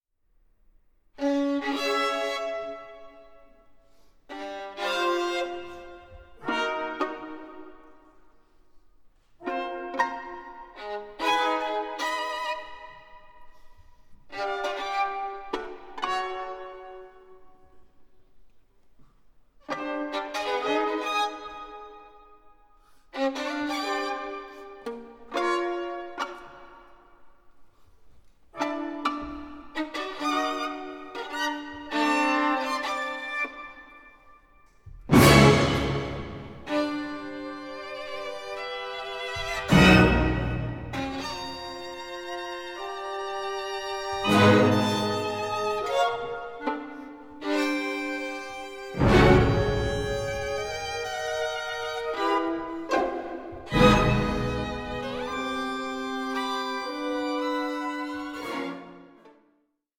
With orchestral textures both radiant and unsettling